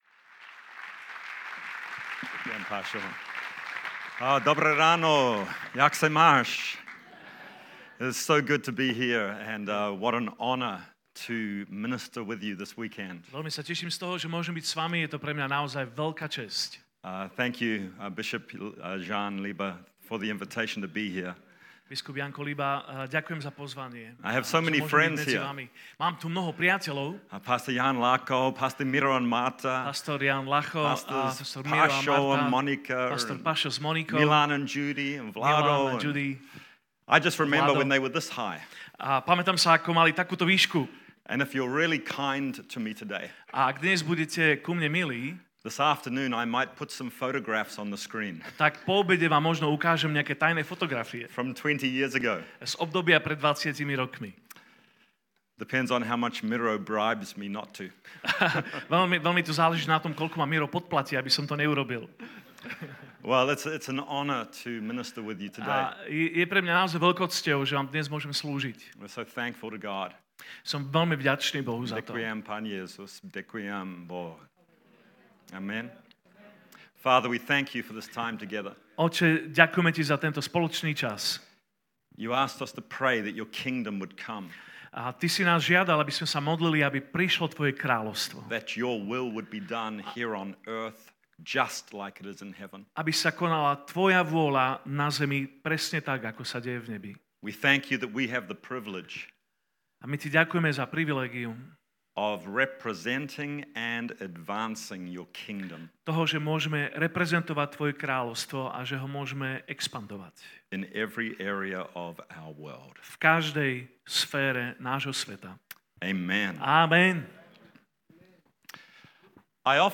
Pravdepodobne najobľúbenejšia téma, na ktorú Ježiš kázal bola "kráľovstvo Božie". Vypočujte si úvodnú kázeň z konferencie Apoštolskej cirkvi 2019